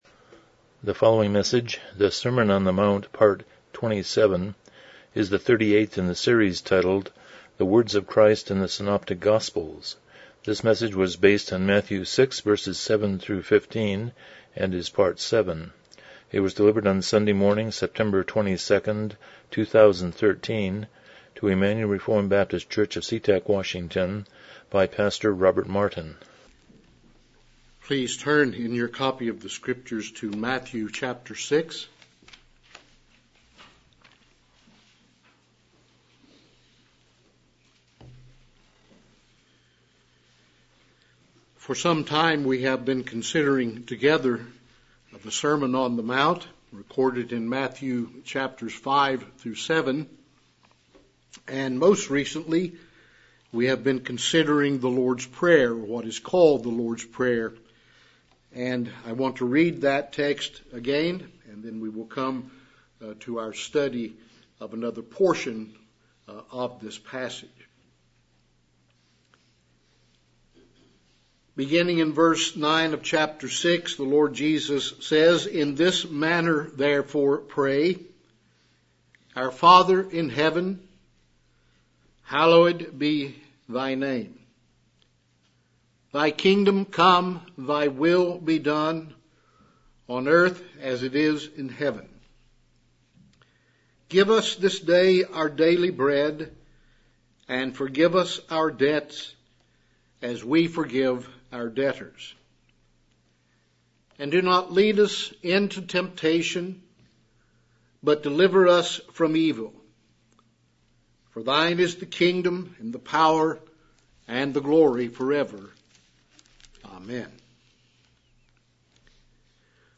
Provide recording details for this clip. Passage: Matthew 6:7-15 Service Type: Morning Worship